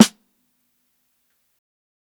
635_SNARE_MED.wav